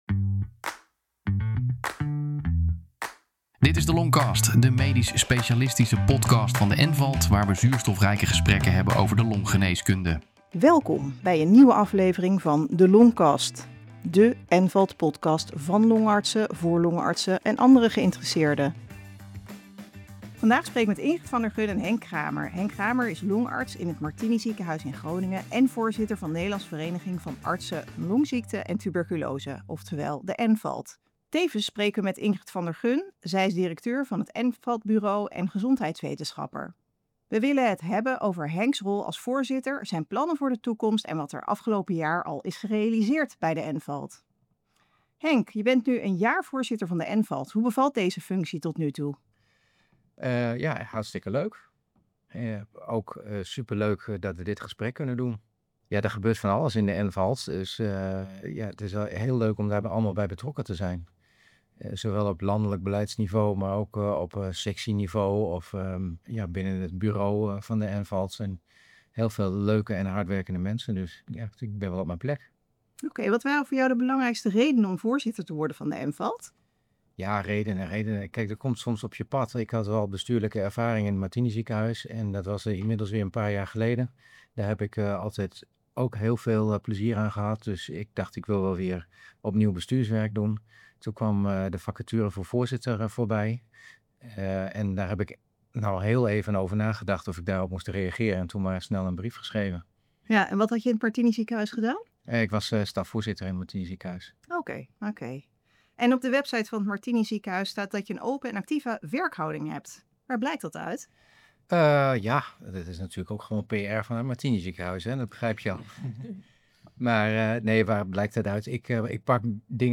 In deze speciale eindejaars aflevering gaan we in gesprek met het NVALT-bestuur.